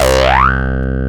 FILTR SWP 1F.wav